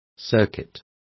Also find out how circuito is pronounced correctly.